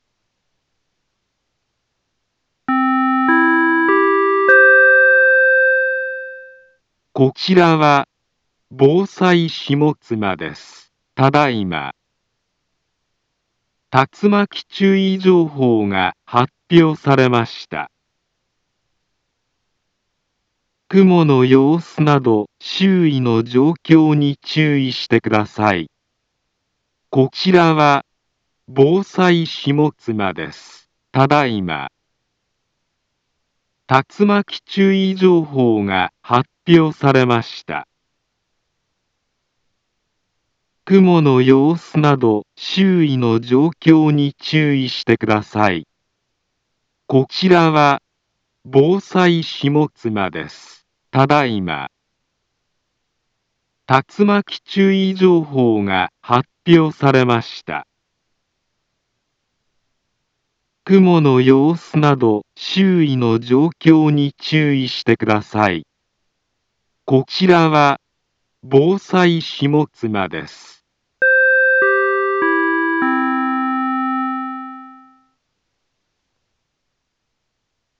Back Home Ｊアラート情報 音声放送 再生 災害情報 カテゴリ：J-ALERT 登録日時：2023-07-10 18:34:50 インフォメーション：茨城県北部、南部は、竜巻などの激しい突風が発生しやすい気象状況になっています。